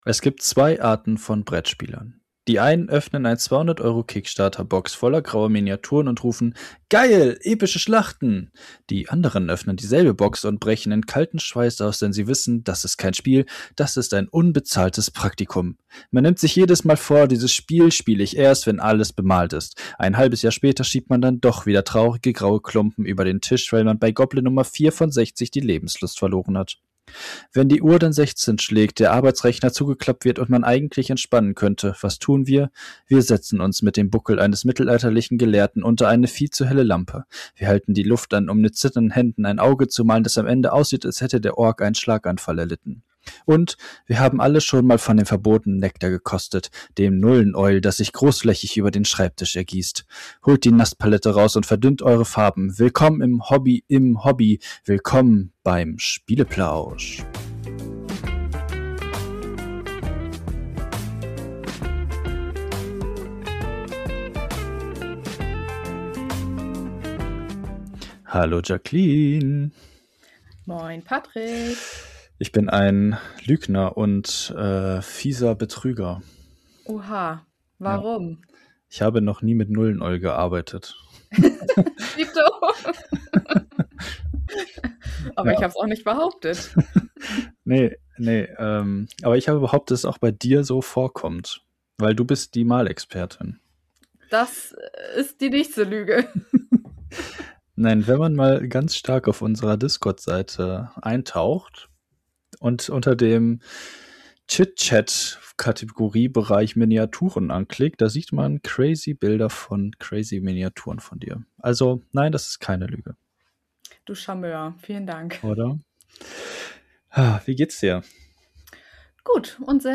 Wir schweifen in dieser Folge und in jeder zukünftigen, gerne ab.